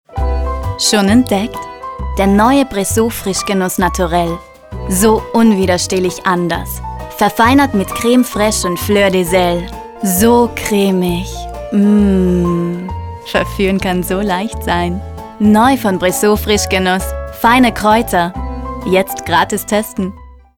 Hablante nativo
austríaco